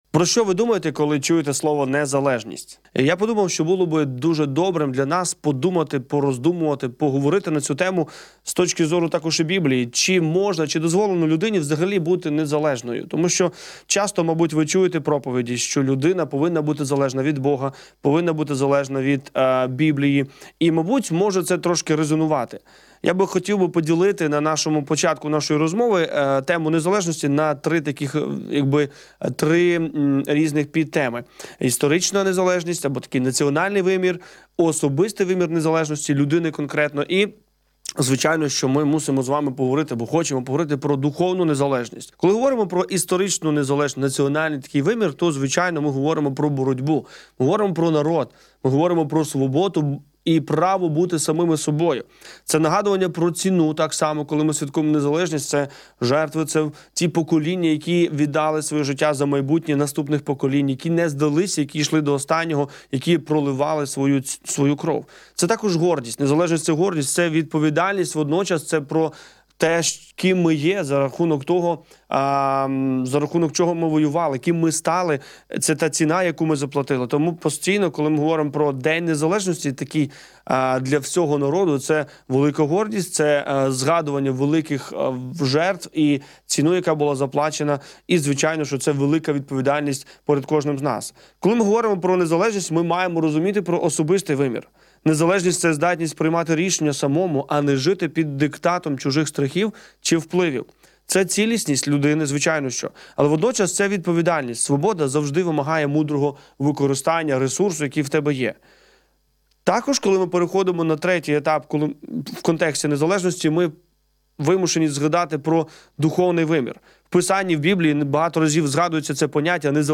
Завантажати запис ефіру на тему: Формула незалежності